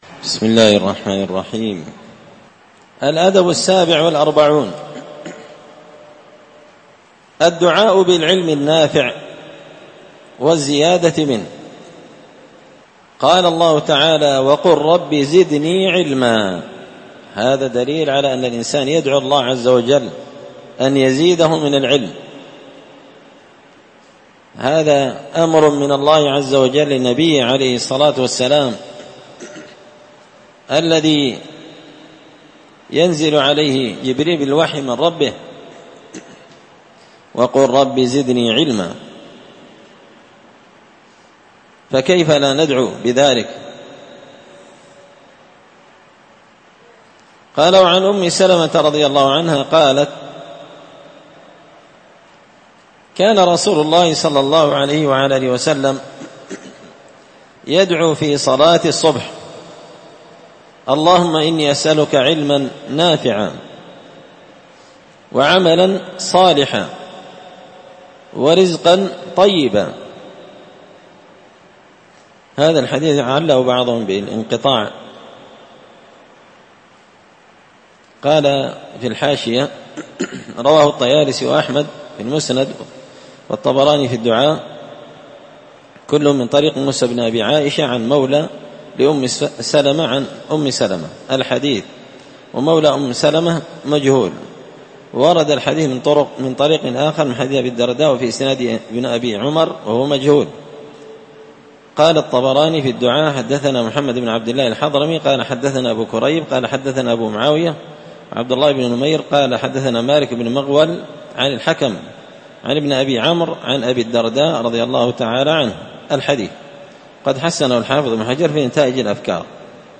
الدرس الخامس والخمسون (55) الأدب السابع والأربعون الدعاء بالعلم النافع والزيادة منه